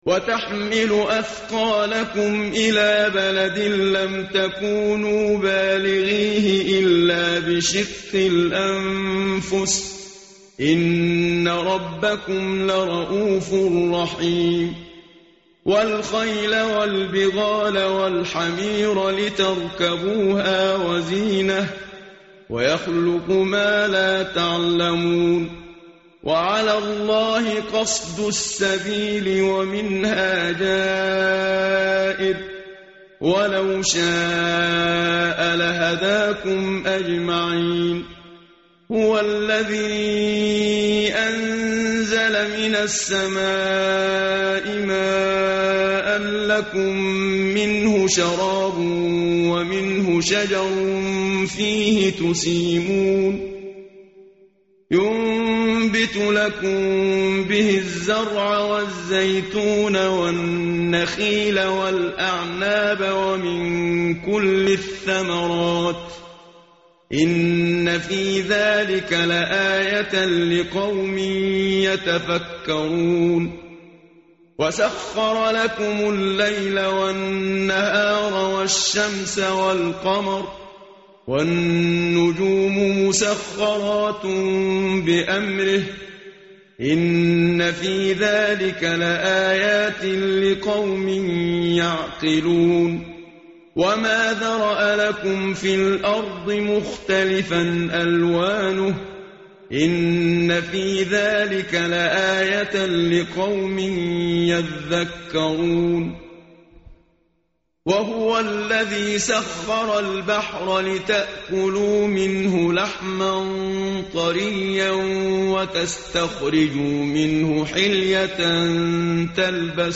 متن قرآن همراه باتلاوت قرآن و ترجمه
tartil_menshavi_page_268.mp3